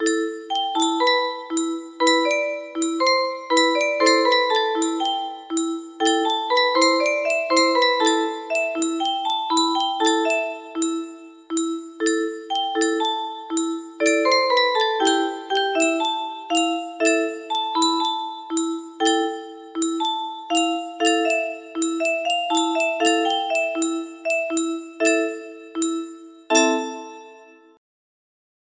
Bir Fırtına Tuttu Bizi - Selanik Türküsü music box melody